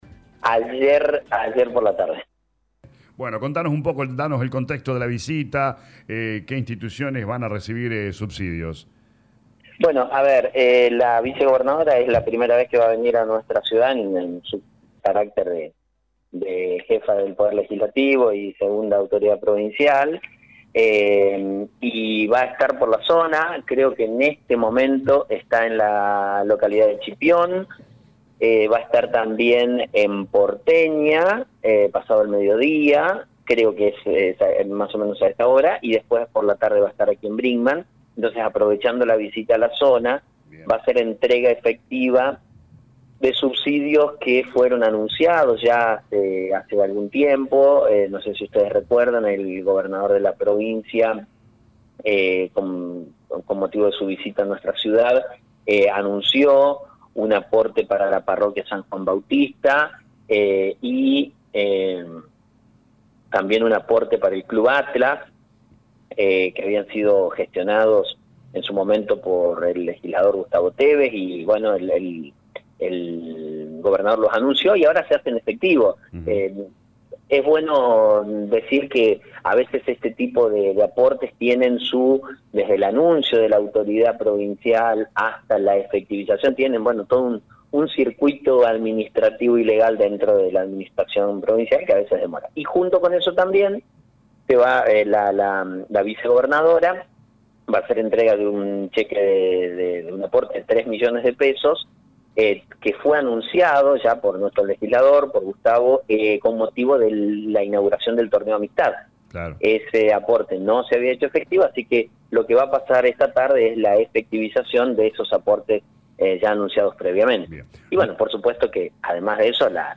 El acto de apertura de sesiones del período de sesiones se realizará el próximo jueves 13 de marzo, según anticipó el intendente Actis en diálogo con LA RADIO 102.9.